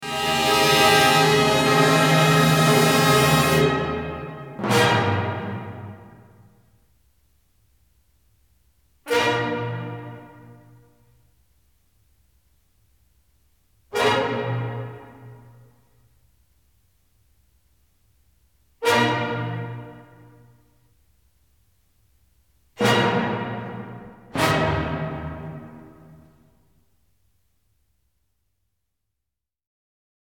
Imaginons la fin de la cinquième symphonie de Sibelius avec les accords entrecoupés de silences, imaginez un peu si le public applaudissait à chaque fois !